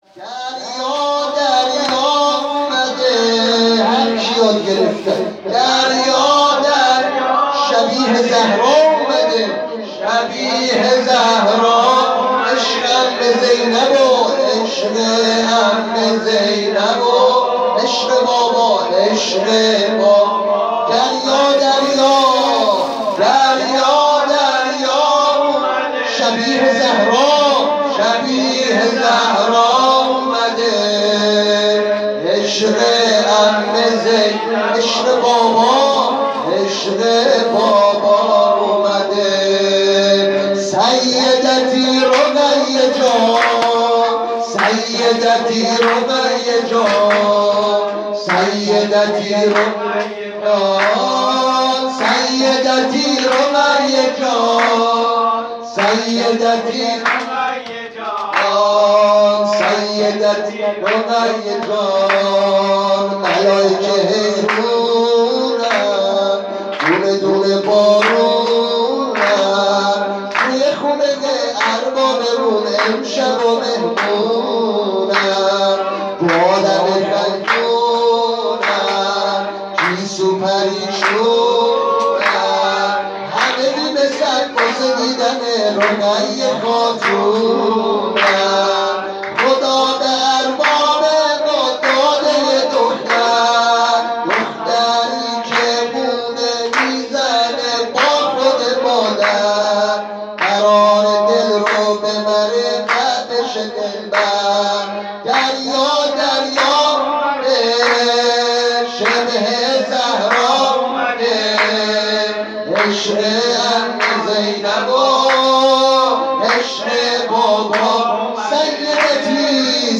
جشن ها